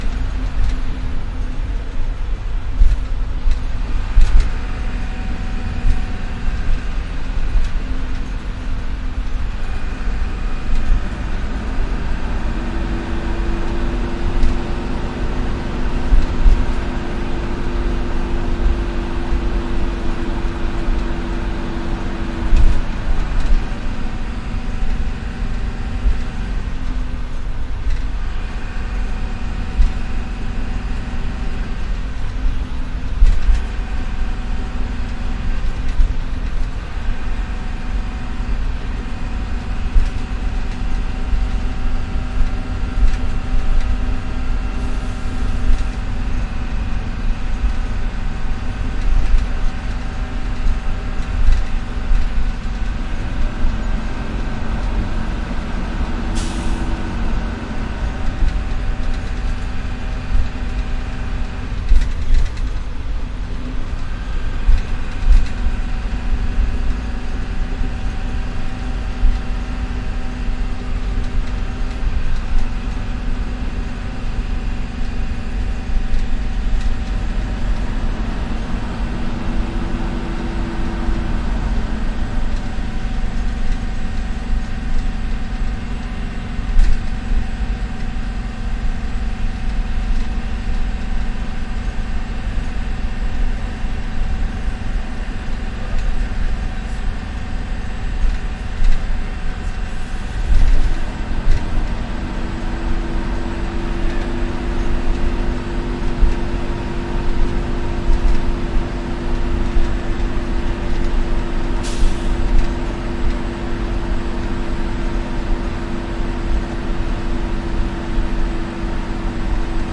描述：汽车真正的庞蒂亚克G5 int驾驶快速高速公路，并通过常规颠簸桥梁减速到怠速和停止.flac